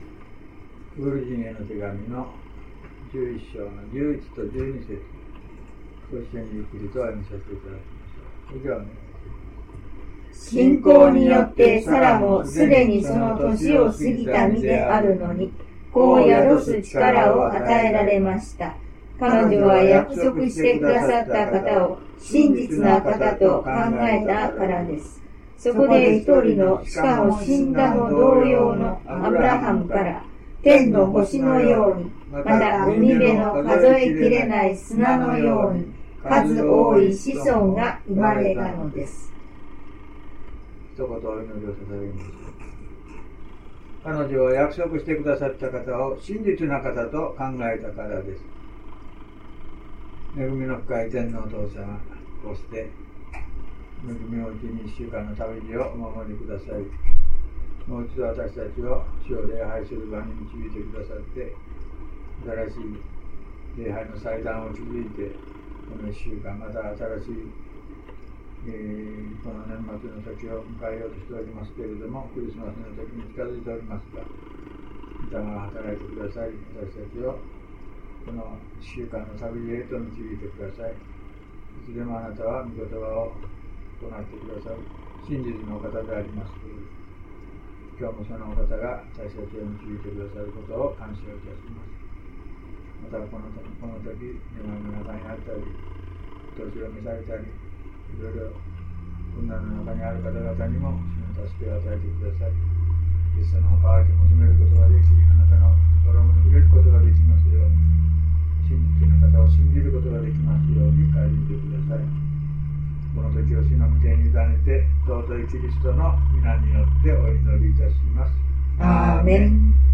礼拝メッセージ
Heroes_of_Faith_16mono.mp3